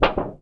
metal_tin_impacts_wobble_bend_06.wav